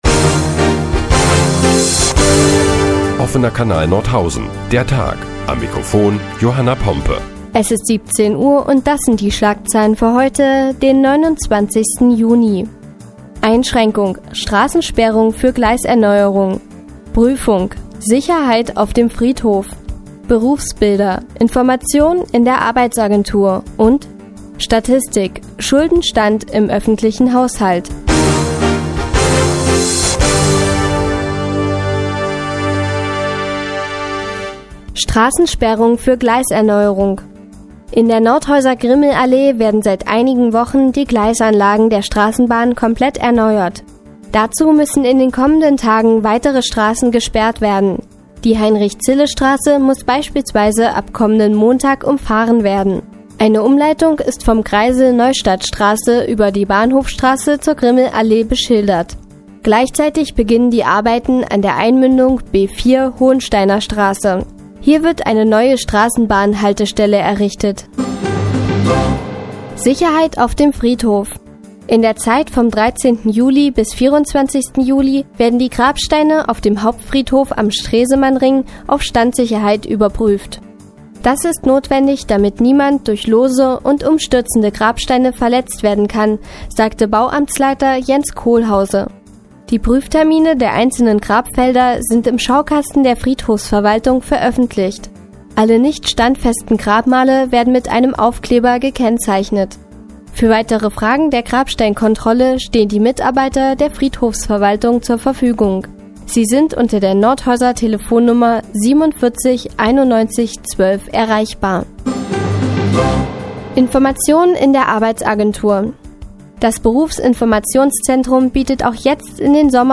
Die tägliche Nachrichtensendung des OKN ist nun auch in der nnz zu hören. Heute geht es unter anderem um mehr Sicherheit auf dem Friedhof und um den Schuldenstand im Öffentlichen Haushalt.